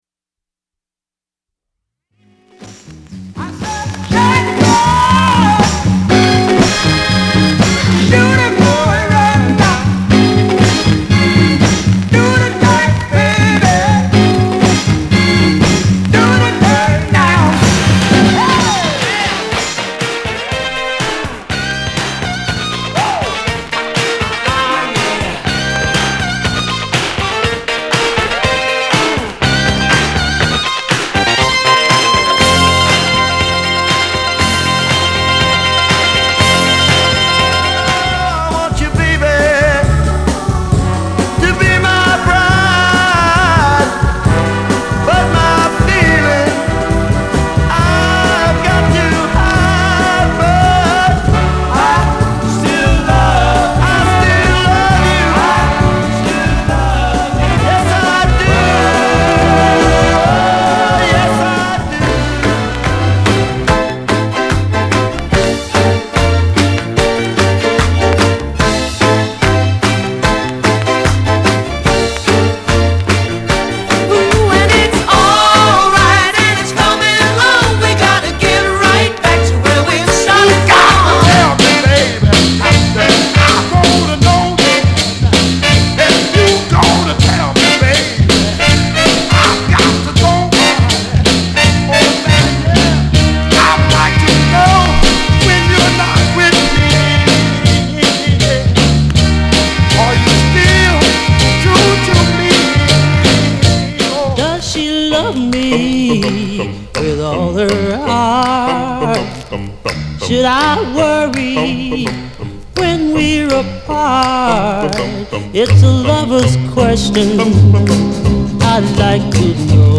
R&B、ソウル
/盤質/EX/やや傷あり/US PRESS